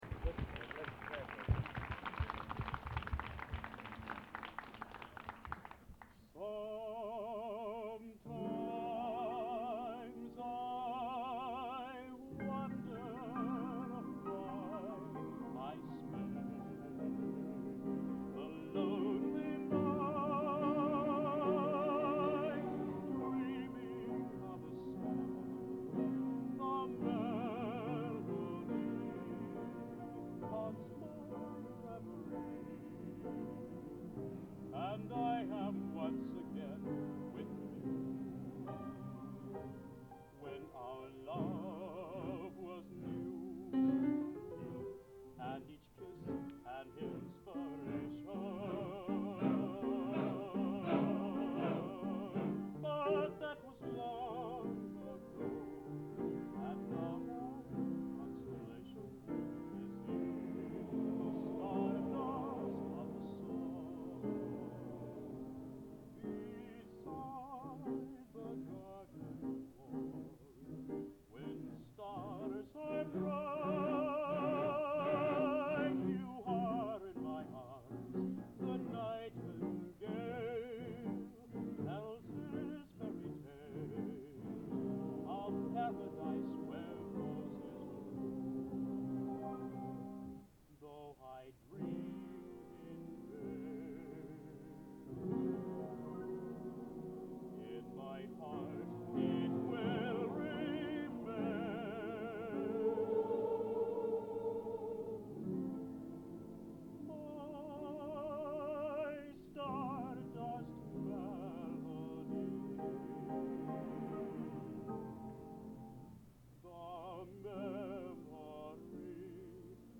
Genre: Popular / Standards | Type: Solo